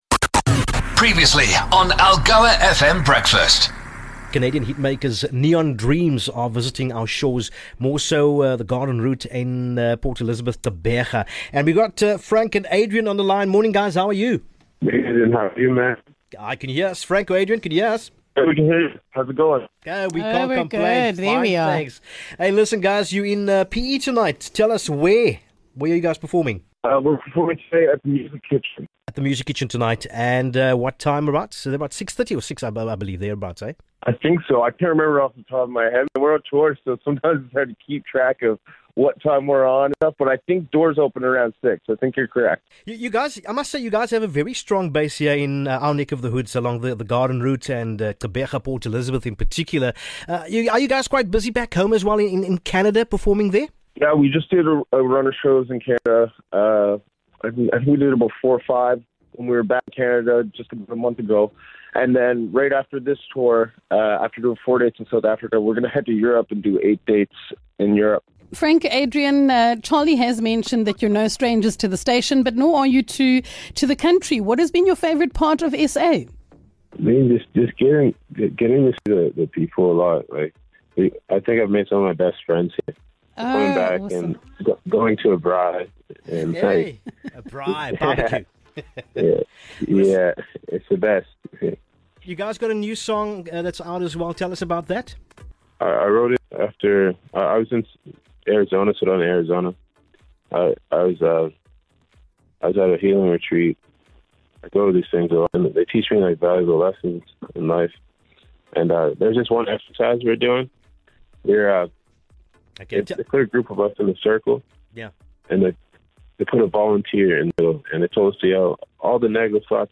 15 Oct Neon Dreams chat to Breakfast ahead of tonight's Music Kitchen show